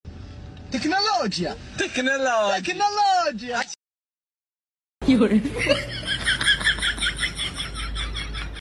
Technologia | Funny Comedy Sound 😃
Download funny comedy meme 🤣 titled Technologia | Funny Comedy Sound 😃 Download mp3 below…
Technologia-Comedy-Sound-effect.mp3